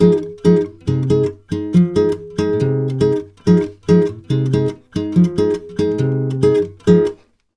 140GTR FM7 5.wav